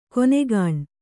♪ konegāṇ